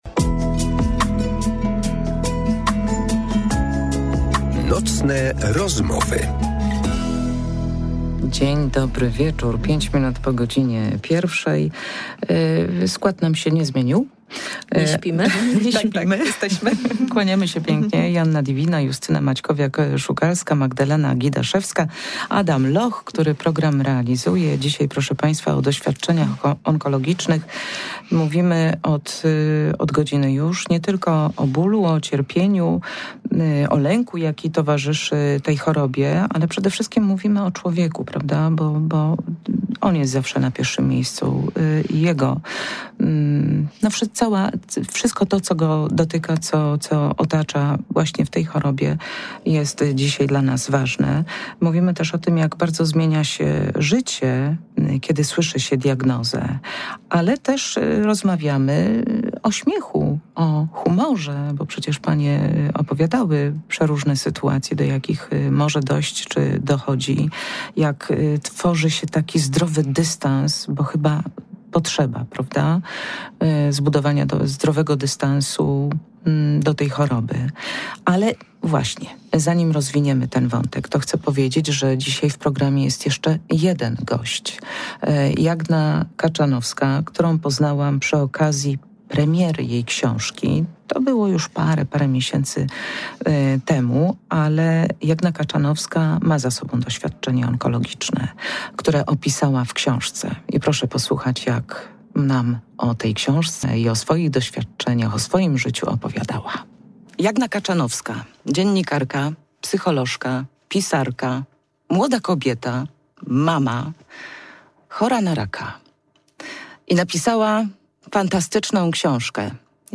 Nocne rozmowy to nowa audycja Radia Poznań. Długie rozmowy oraz pytania łatwe i trudne, ale odpowiedzi zawsze szczere...